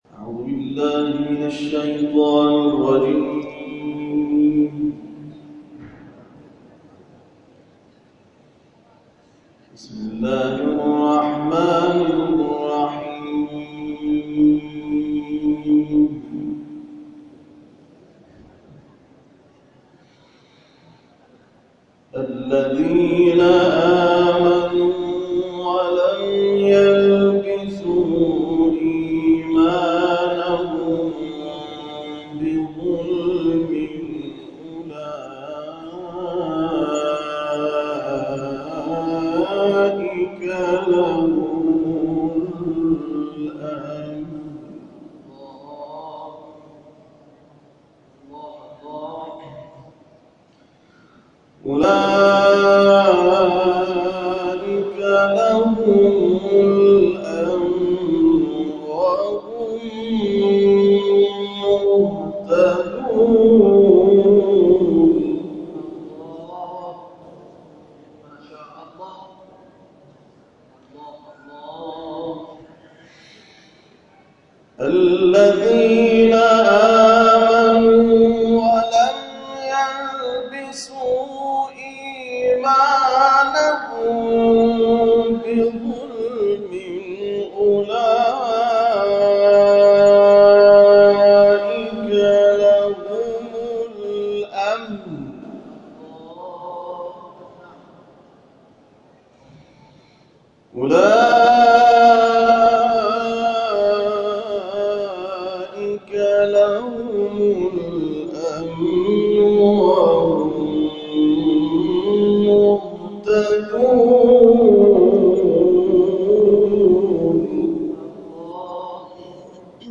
یادآور می‌شود، این محفل، هر هفته در ایوان مقصوره برگزار می‌شود.
در ادامه تلاوت این قاری بین‌المللی ارائه می‌شود.